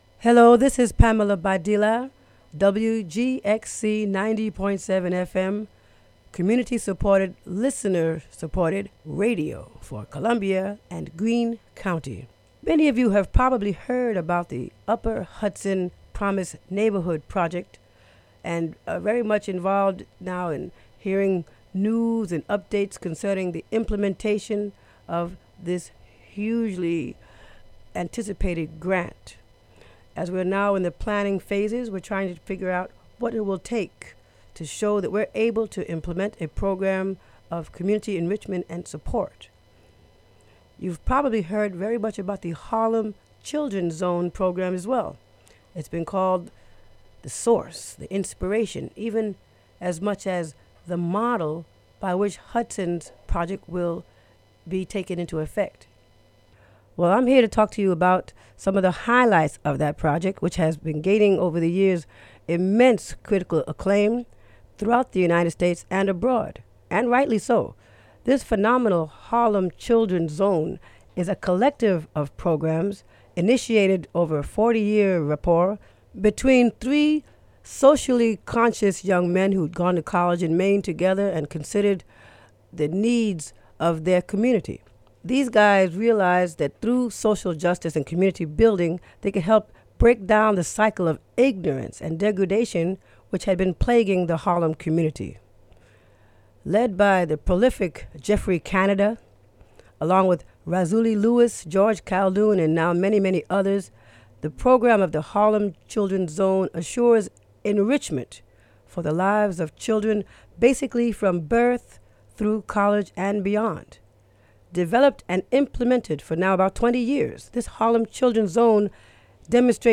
A short preview of audio recorded during a visit to the Harlem Children's Zone